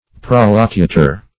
Prolocutor \Prol`o*cu"tor\, n. [L., from proloqui, p. p.